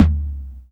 prcTTE44032tom.wav